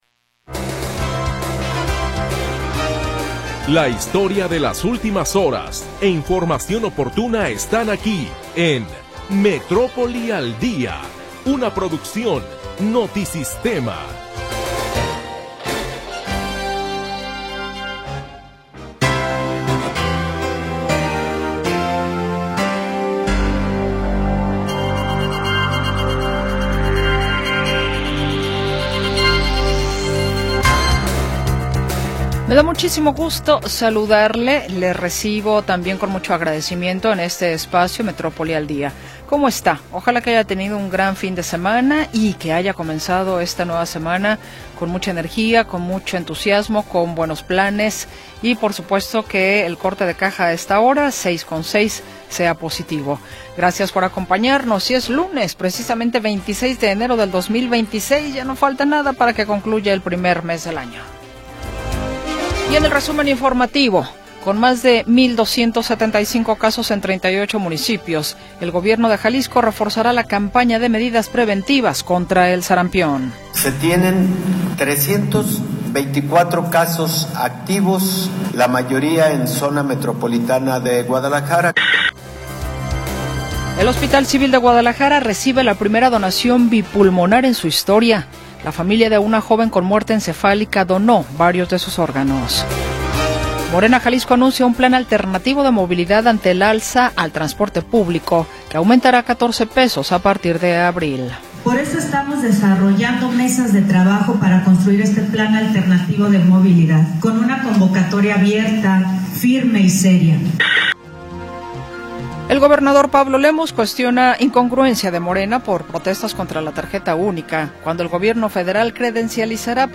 Primera hora del programa transmitido el 26 de Enero de 2026.